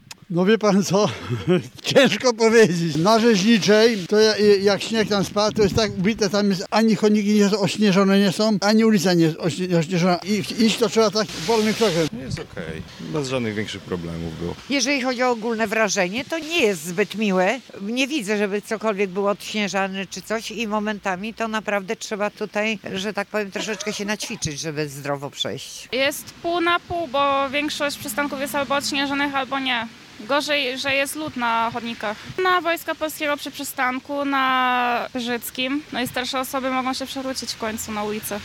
Spytaliśmy o to mieszkańców miasta, którzy na co dzień poruszają się komunikacją miejską i mierzą się z zaśnieżonym miastem.
– mówią mieszkańcy Stargardu.